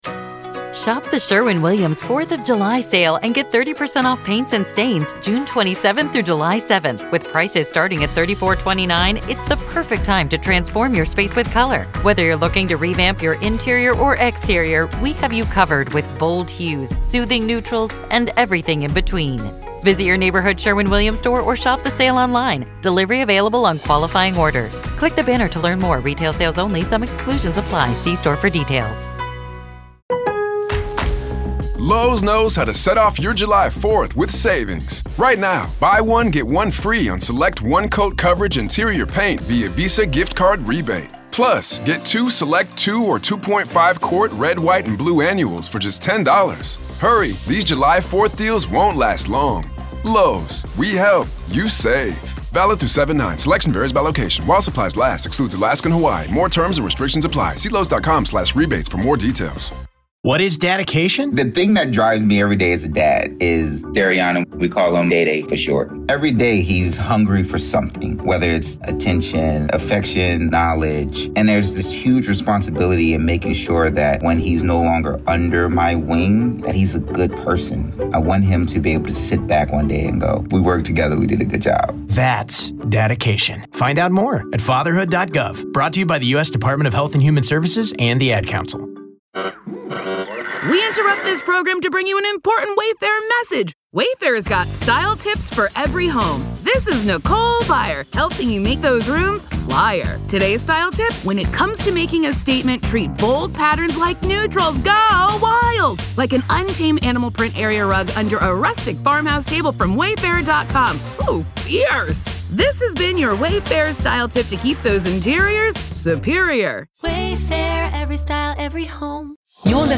The discussion begins with the story